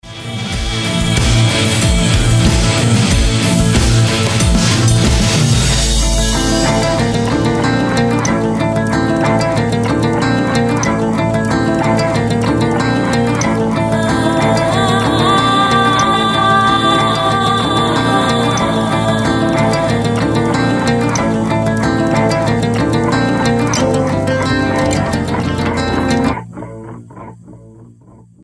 • Качество: 320, Stereo
гитара
бас-гитара